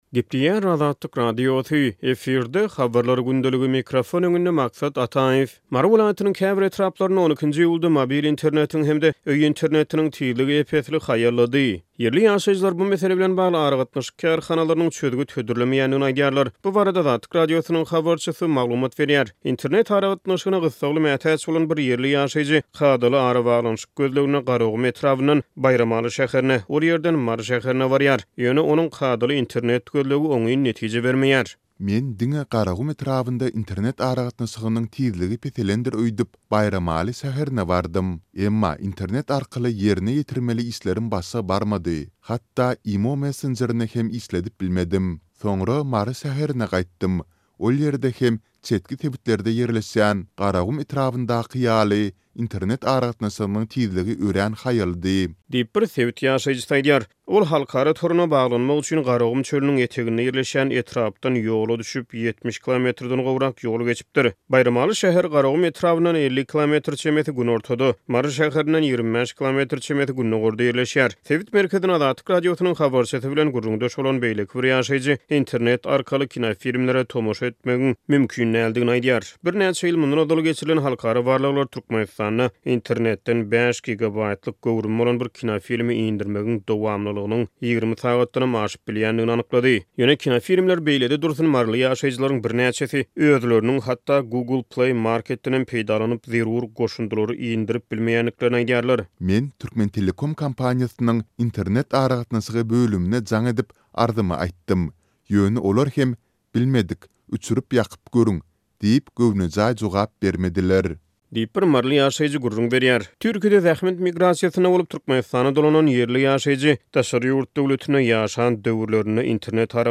Bu barada Azatlyk Radiosynyň habarçysy maglumat berýär.